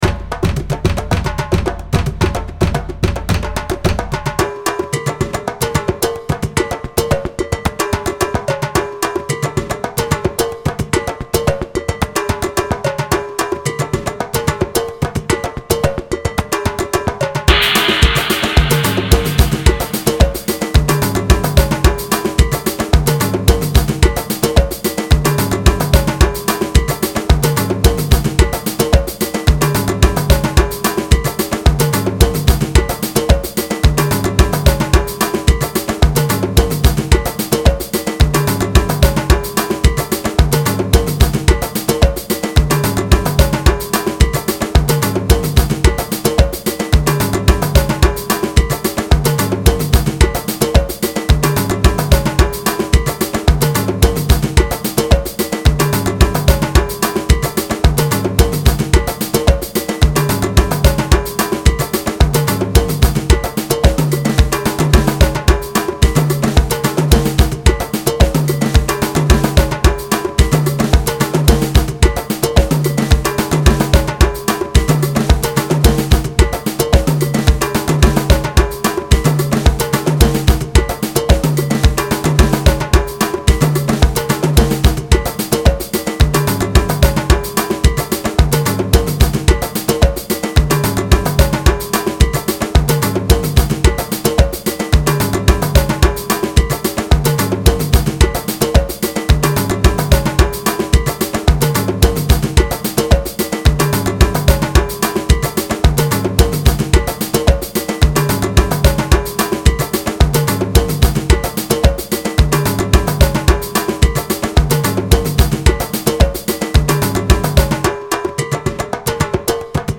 The Drums Sound